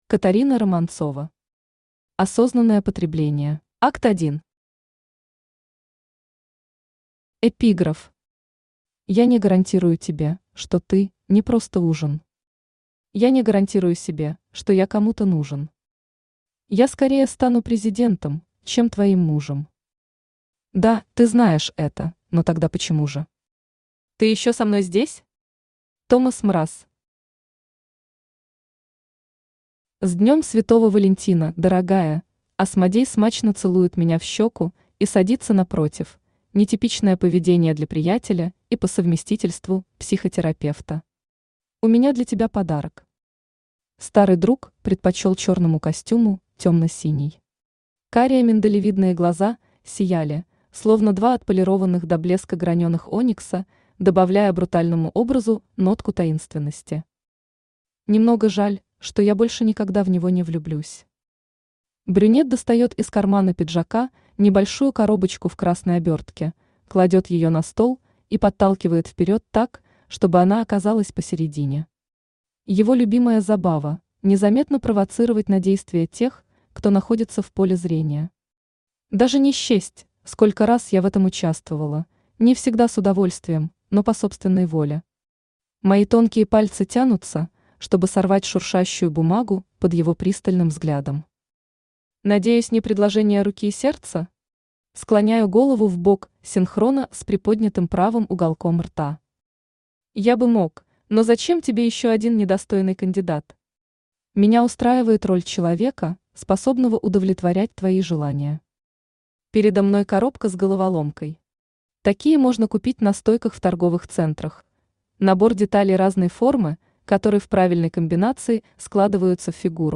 Aудиокнига Осознанное потребление Автор Катарина Романцова Читает аудиокнигу Авточтец ЛитРес.